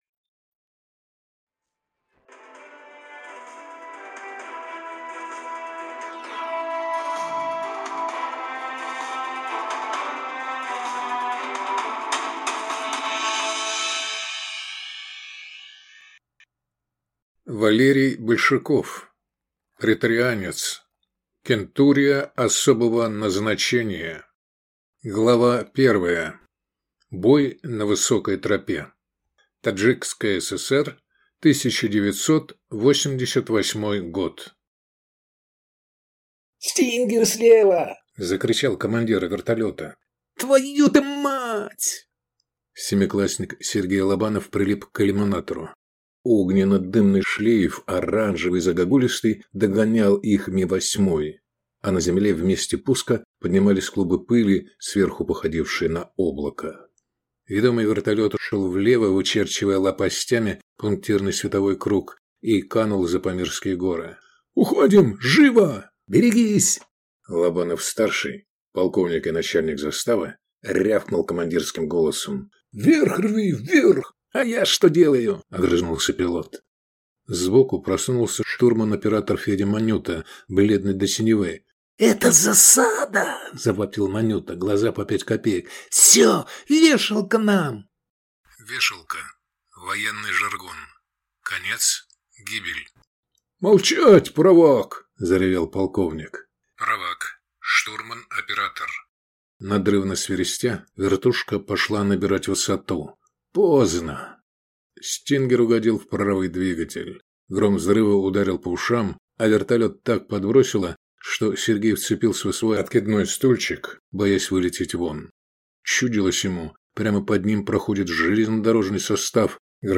Аудиокнига Преторианец. Кентурия особого назначения | Библиотека аудиокниг